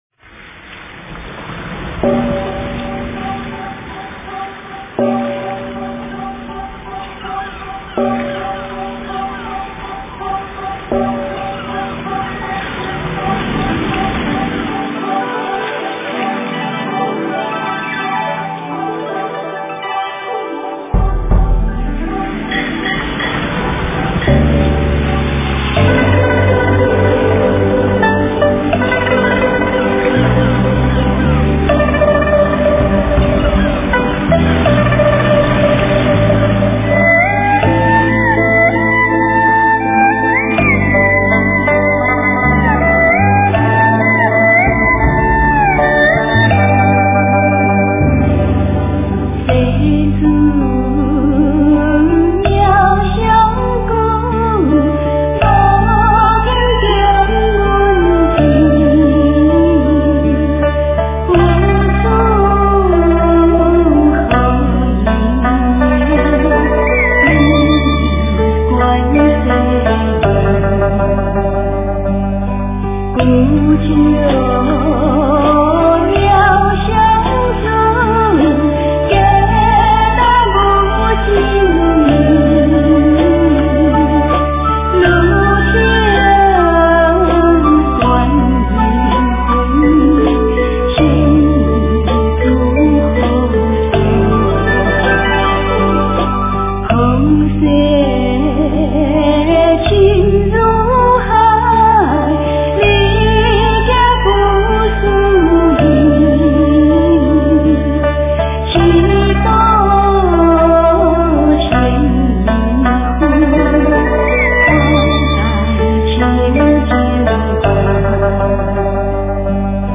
妙法莲华观世音菩萨普门品 - 诵经 - 云佛论坛
佛音 诵经 佛教音乐 返回列表 上一篇： 无量寿经-上 下一篇： 地藏经-分身集会品第二 相关文章 断桥残雪--许嵩 断桥残雪--许嵩...